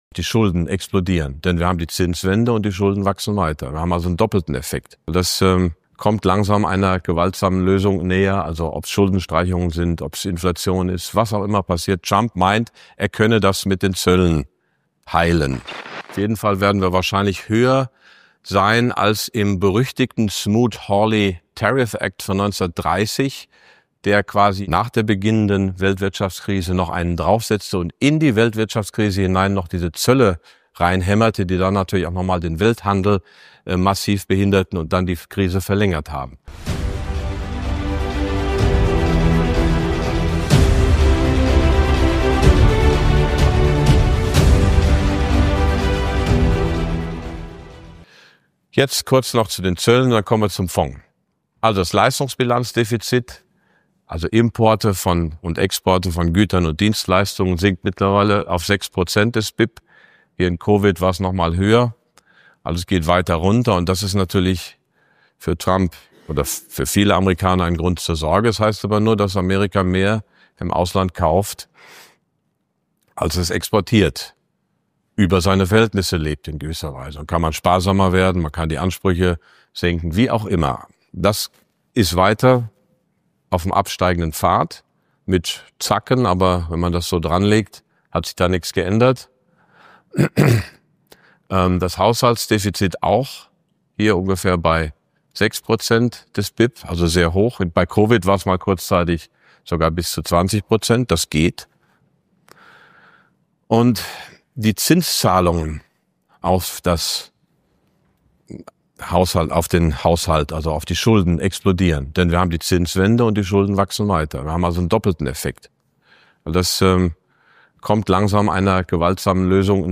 Beim Kapitaltag im April 2025 analysierte Prof. Dr. Max Otte die